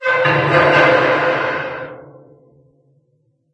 Звук далекий скрип метала.